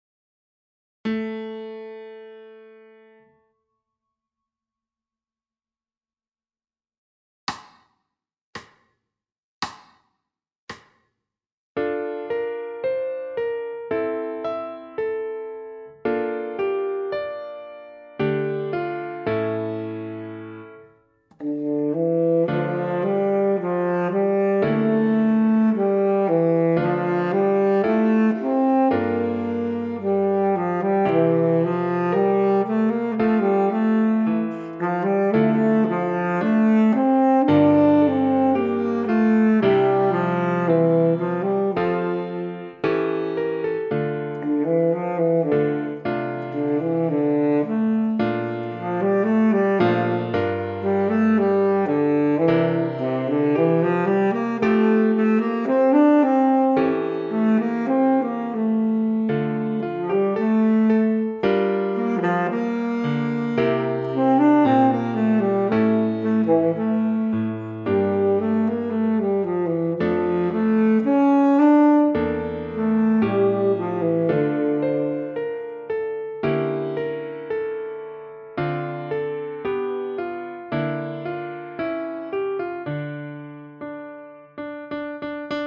Pour saxophone alto et piano
Ecouter ici 2 extraits (saxo ténor) :